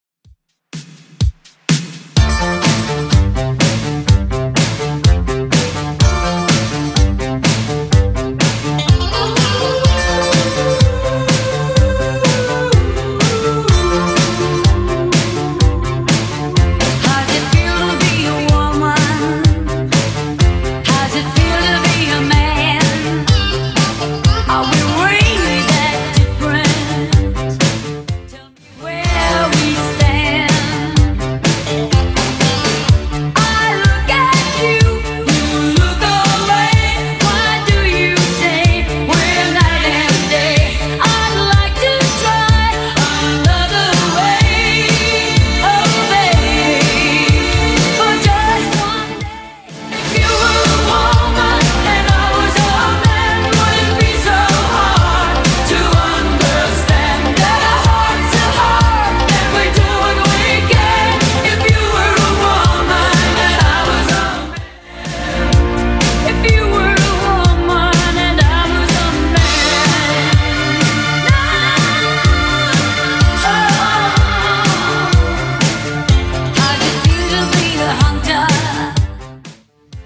Genre: 80's
BPM: 120